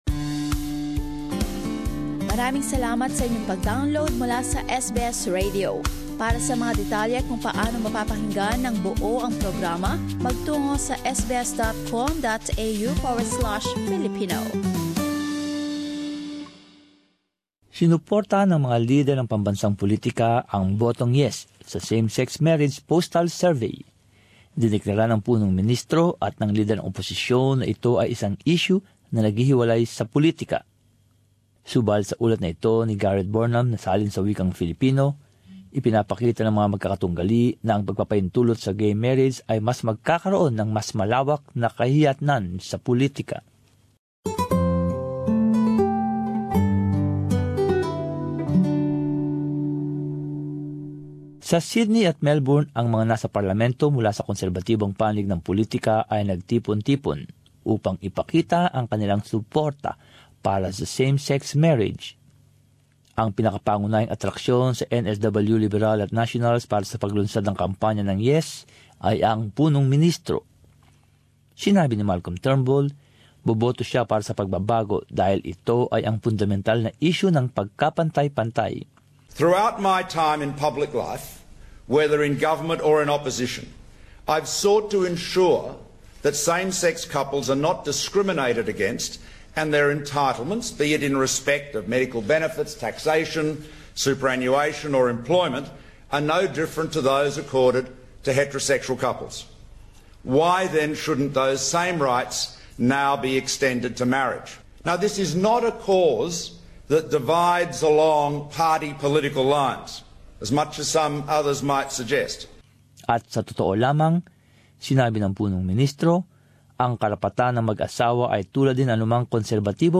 But as this report shows, opponents maintain allowing gay marriage will have wider social consequences.